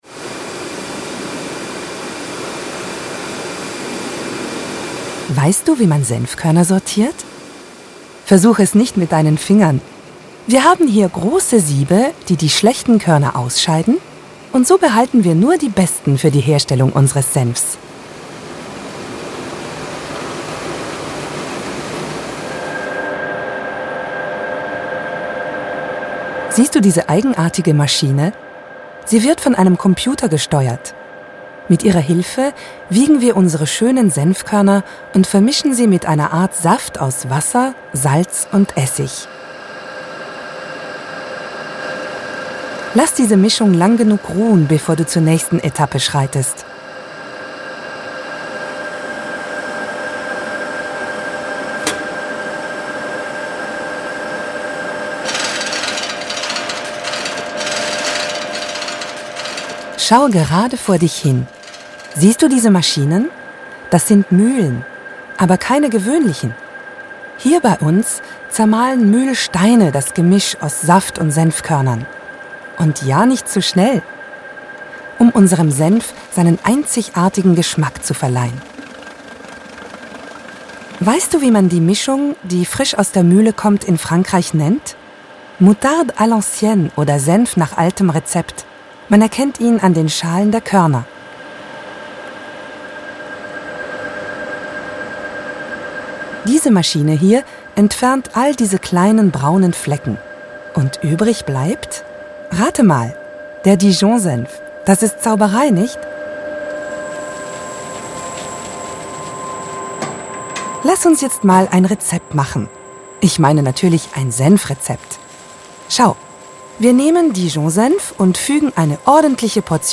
Voix off
10 - 80 ans - Mezzo-soprano